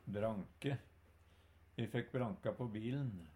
branke - Numedalsmål (en-US)